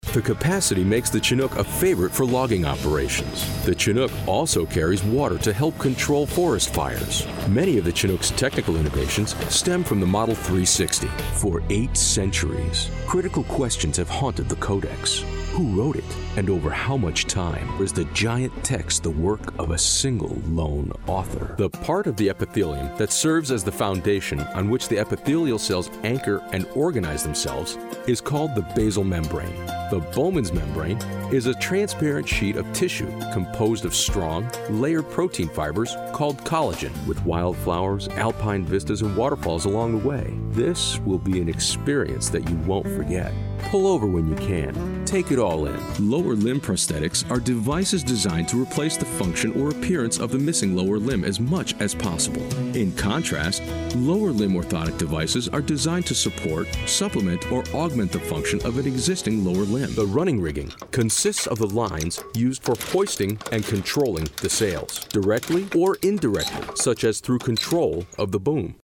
Warm, friendly, guy next door, conversational, commanding, character,
Sprechprobe: Industrie (Muttersprache):